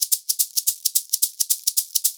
110 SHAKERS6.wav